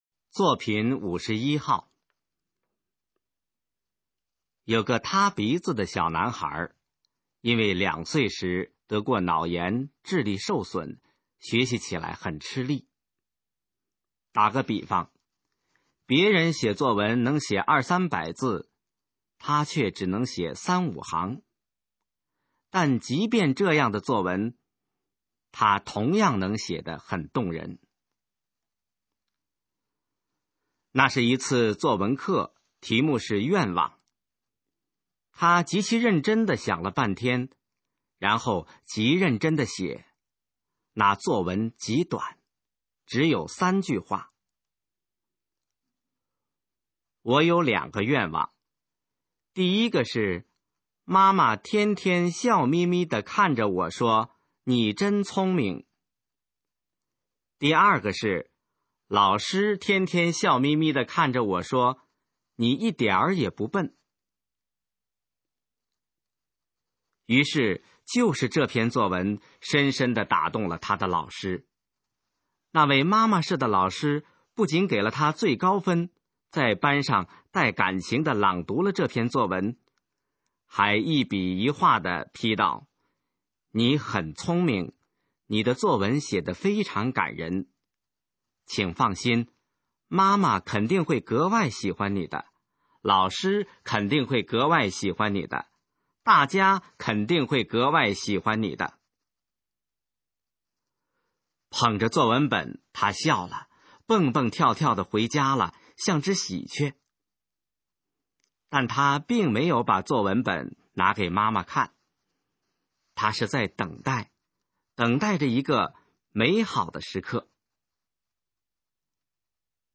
《一个美丽的故事》示范朗读